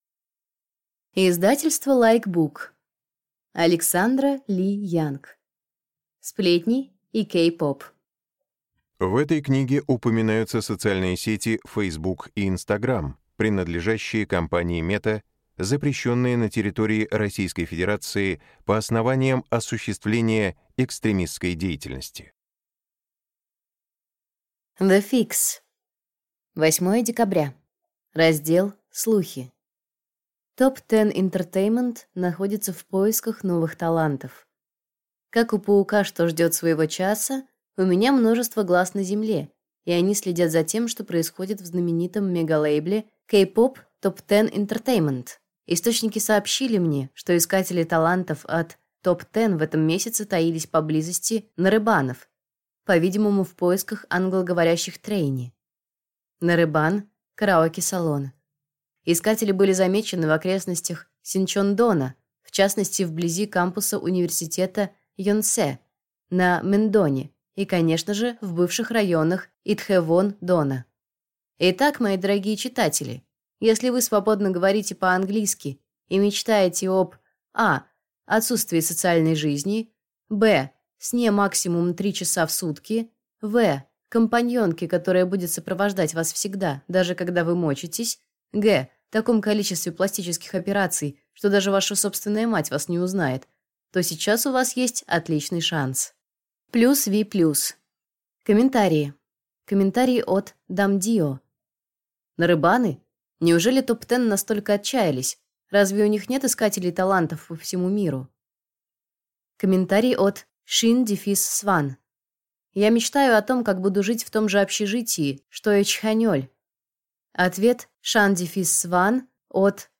Аудиокнига Сплетни и K-pop | Библиотека аудиокниг